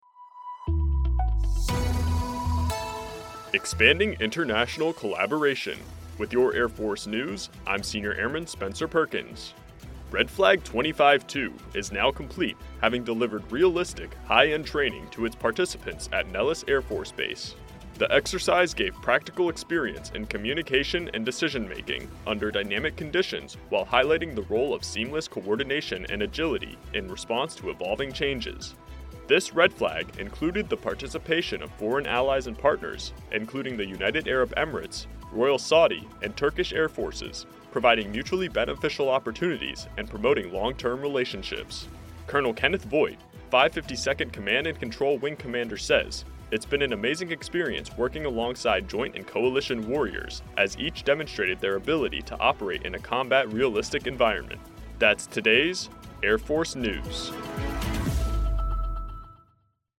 Features audio news stories involving U.S. Air Force technology, personnel, and operations around the globe.